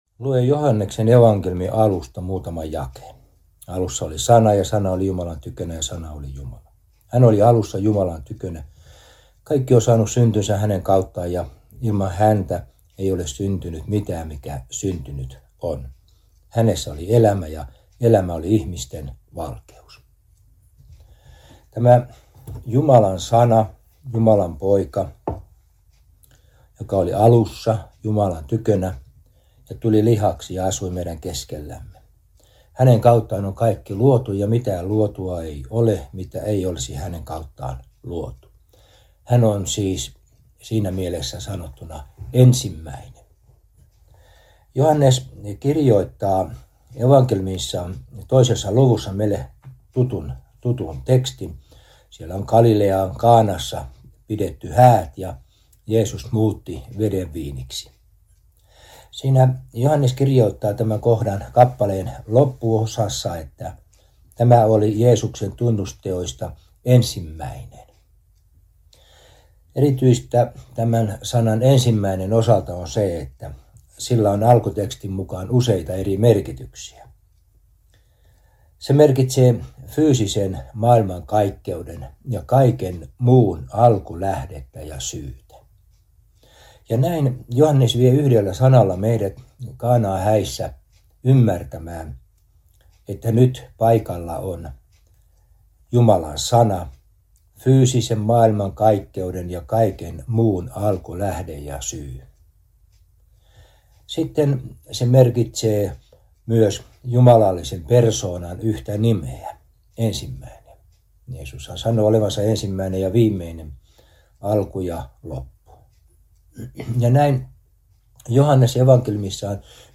hartauspuhe